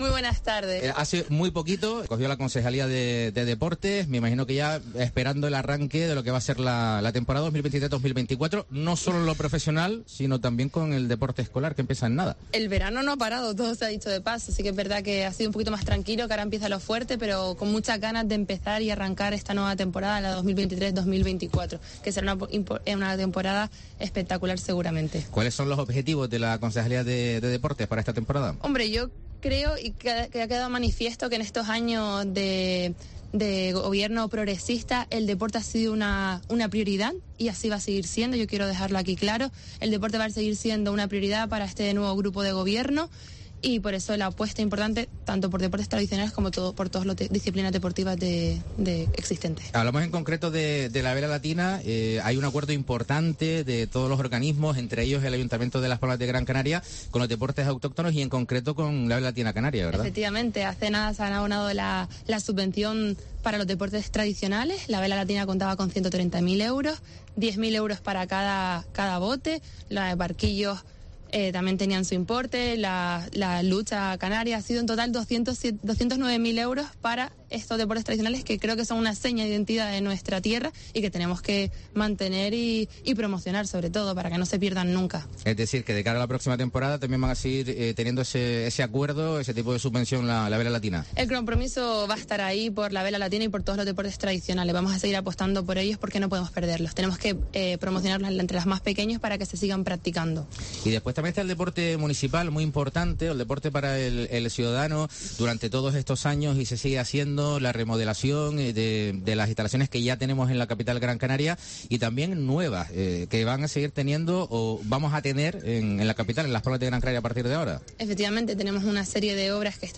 Entrevista a Carla Campoamor, concejala de deportes del Ayuntamiento de Las Palmas de Gran Canaria